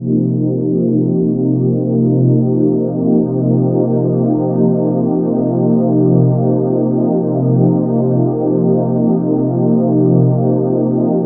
Index of /90_sSampleCDs/USB Soundscan vol.13 - Ethereal Atmosphere [AKAI] 1CD/Partition A/01-AMBIANT A